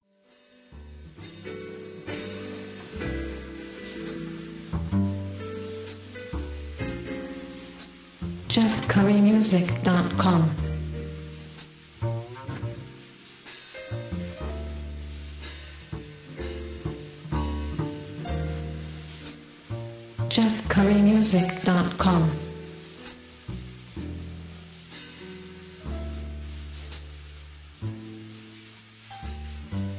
Slow jazz ballad with a smokey, dark air about it.